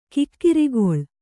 ♪ kikkirigoḷ